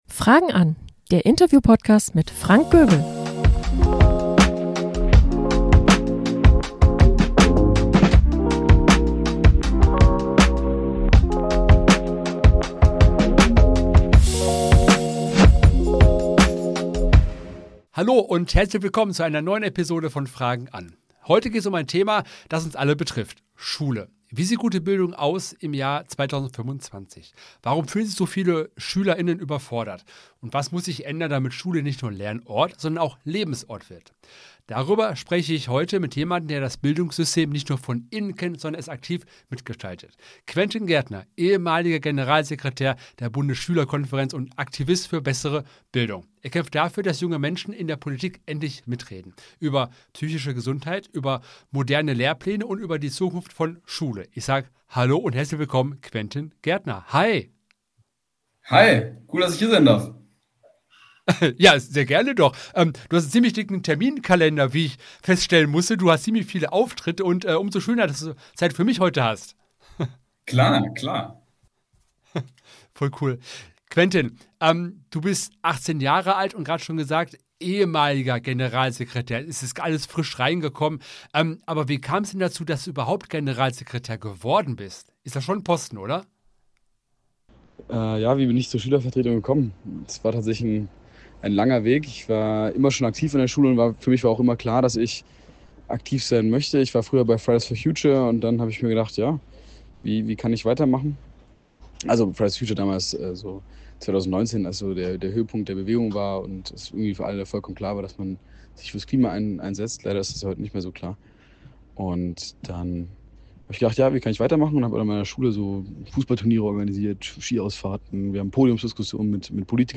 Ich spreche mit ihm über die dringendsten Themen in der Schulpolitik.